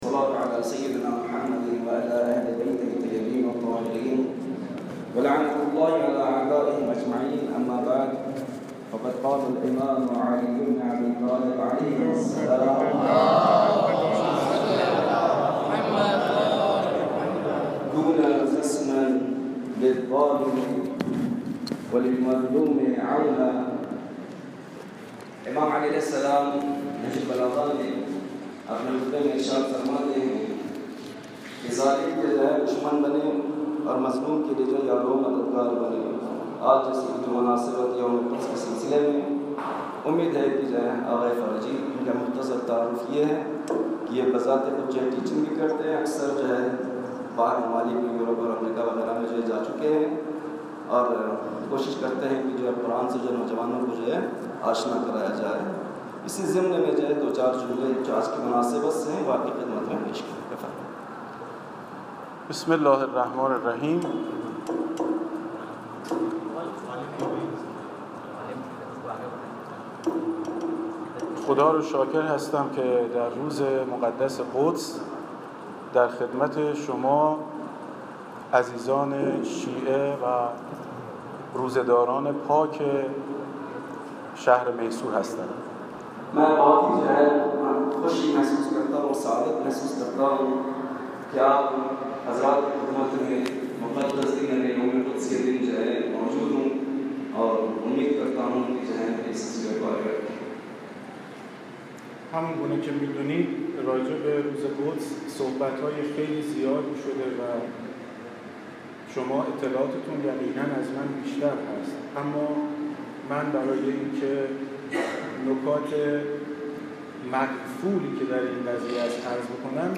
سخنان
در روز قدس